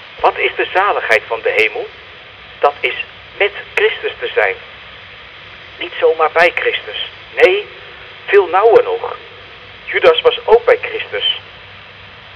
PLAY SOUND   Not many churches use the Church Radio band for broadcasting their services.